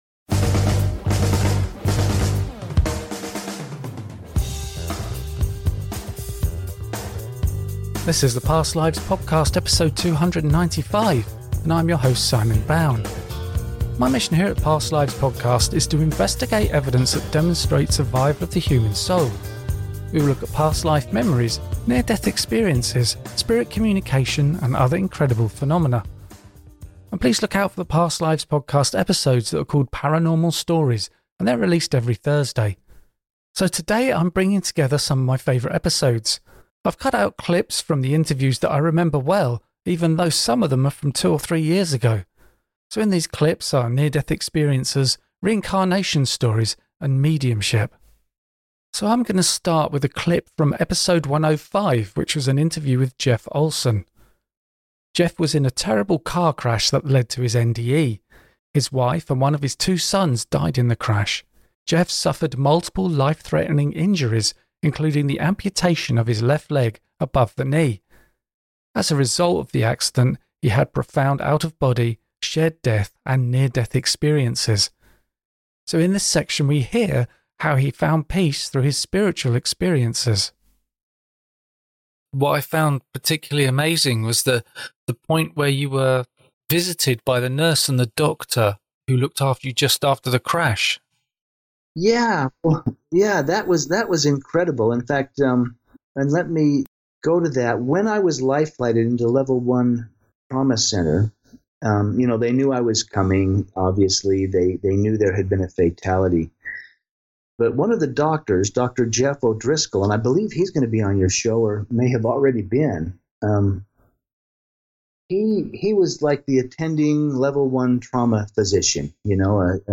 There are interviews that I recorded that stand out to me as particulalry interesting and in this episode I have cut out clips from the interviews and put them together in this compilation.